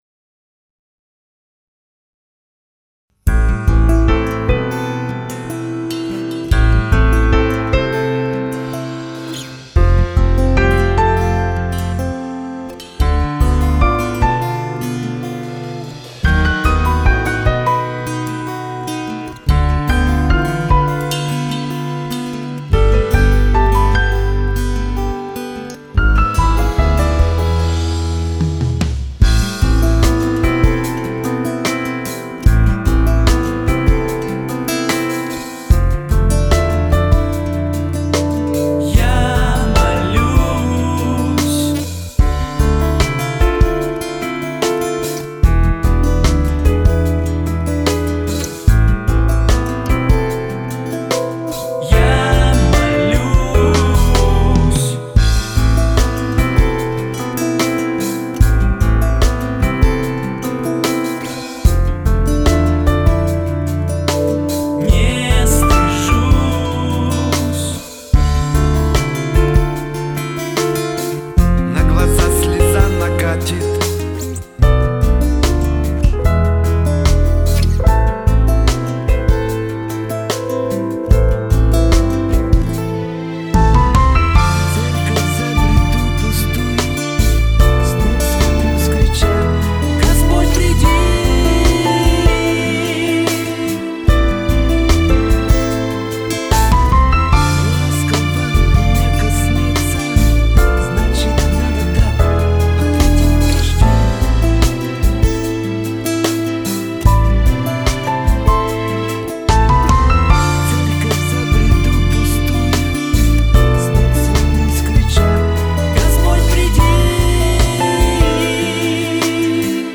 [ Скачать фонограмму (13.74 Mb) ·
Скачать фонограмму с бэк-вокалом (13.74Mb) ]